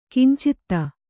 ♪ kincitta